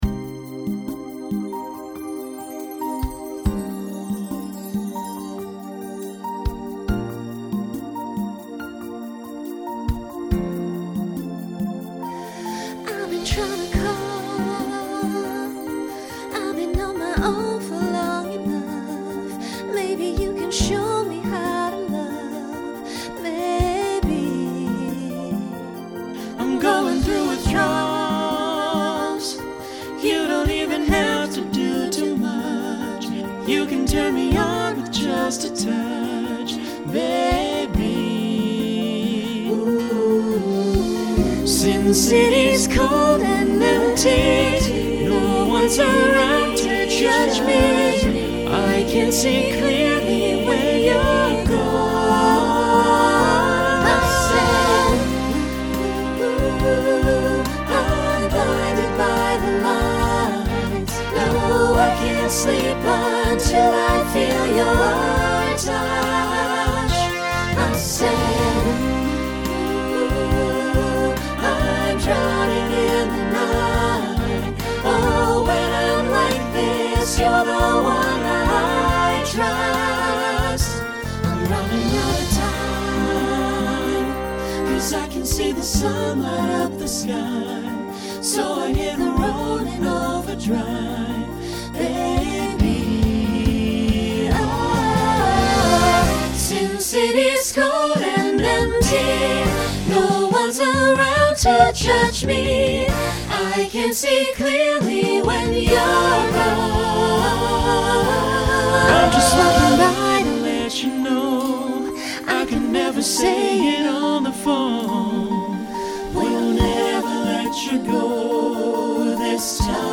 Genre Rock Instrumental combo
Show Function Ballad Voicing SATB